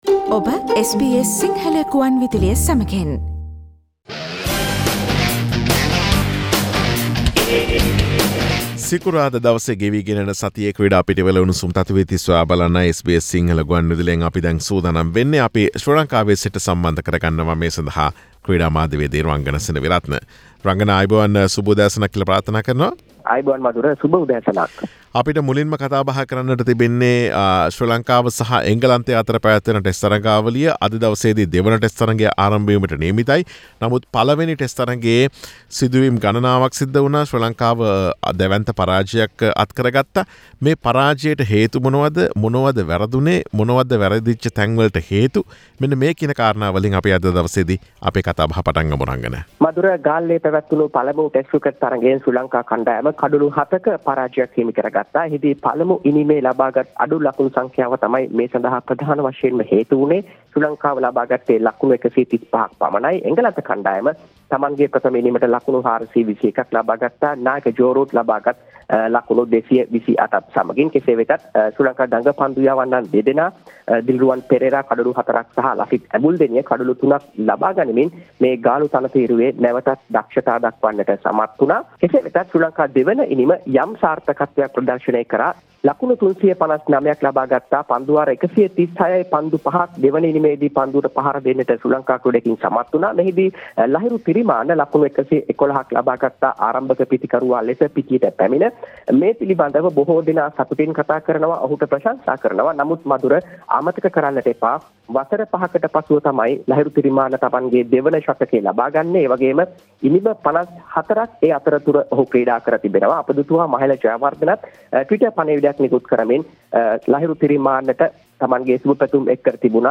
SBS Sinhala Sports Wrap